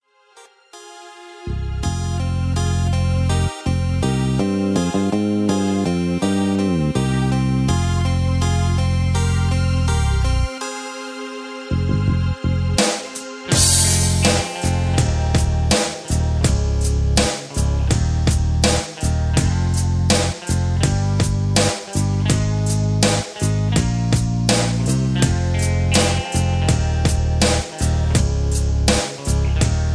karaoke, rap, r and b, backing tracks